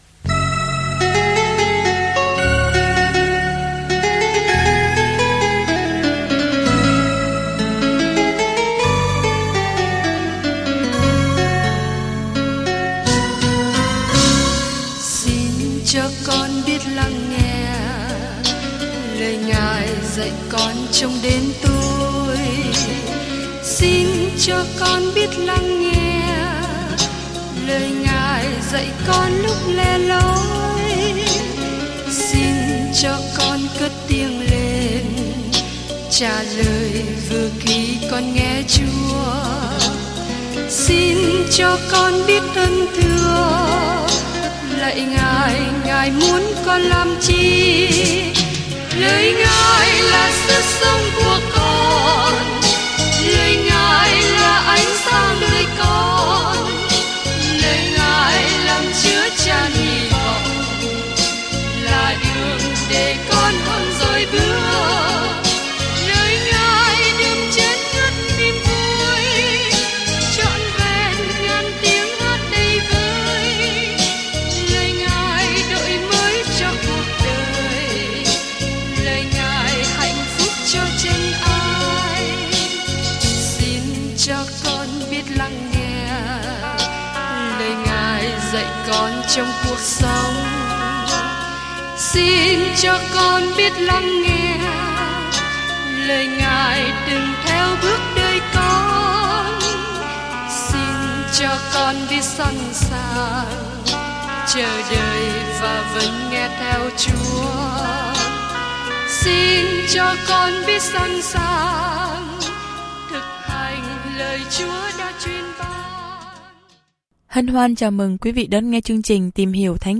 Kinh Thánh II Cô-rinh-tô 6:11-18 Ngày 8 Bắt đầu Kế hoạch này Ngày 10 Thông tin về Kế hoạch Niềm vui của các mối quan hệ trong thân thể Chúa Kitô được nhấn mạnh trong lá thư thứ hai gửi tín hữu Cô-rinh-tô khi bạn nghe đoạn ghi âm nghiên cứu và đọc những câu chọn lọc từ lời Chúa. Du lịch hàng ngày qua 2 Cô-rinh-tô khi bạn nghe nghiên cứu âm thanh và đọc những câu chọn lọc từ lời Chúa.